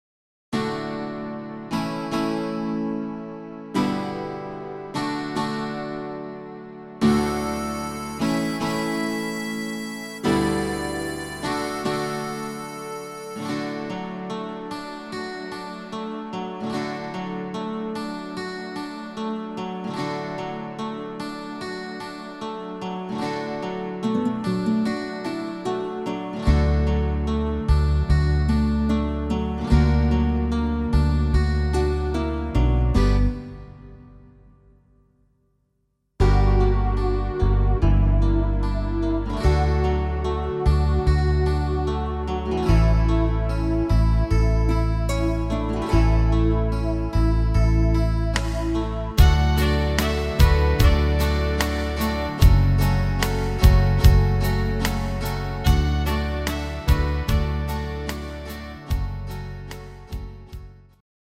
Rhythmus  Slow
Art  Englisch, Oldies